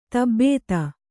♪ tabbēta